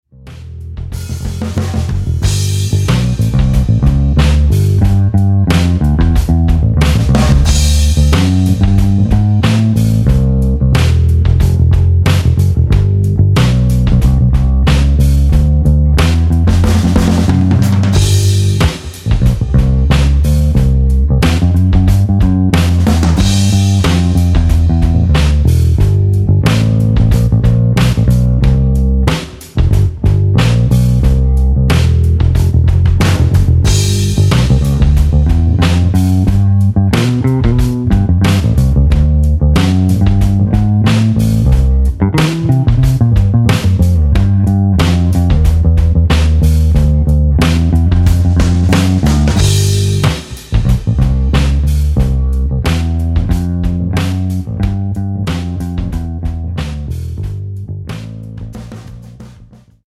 (Ibanez EB3 med Tomastik flats, båda mikarna, inspelat i Cubase genom Joe Meeq MQ3 och Guitar Rig)
Skönt ljud, och trevligt basspel!